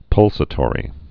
(pŭlsə-tôrē)